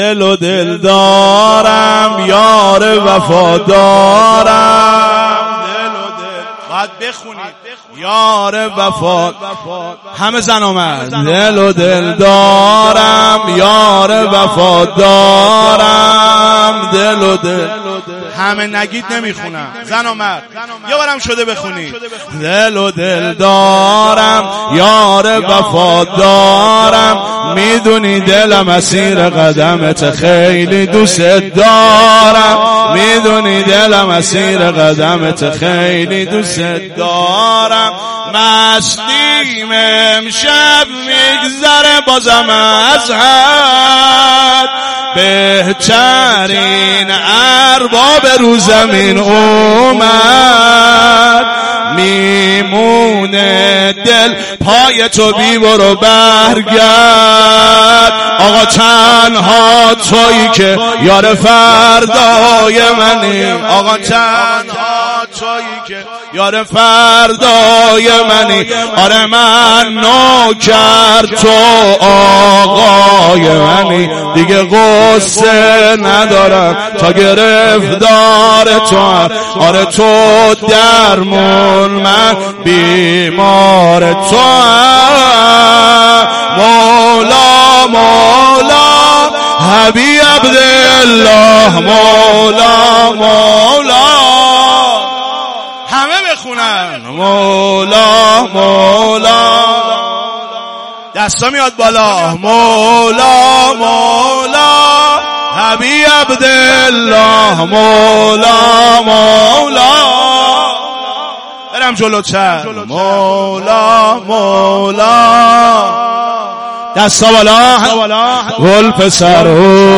مولودی خوانی
مولودی-خوانی.mp3